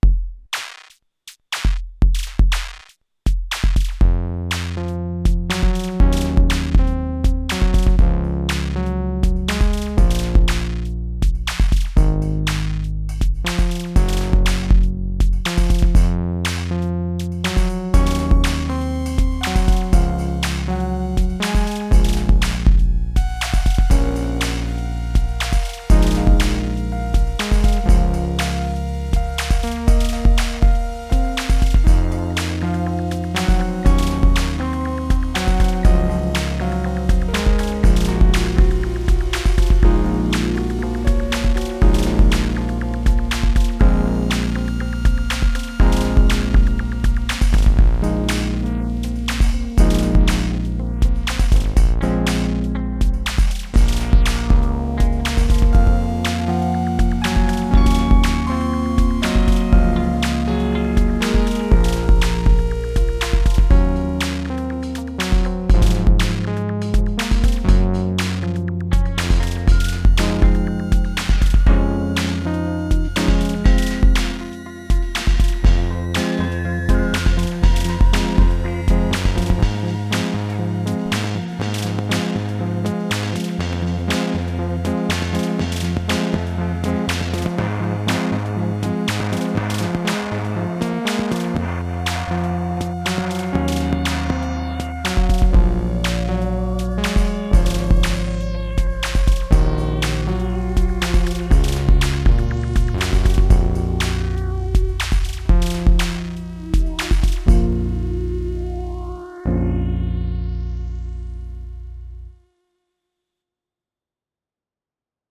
Some random shit. Sloppy but the promise is there for how I want to work.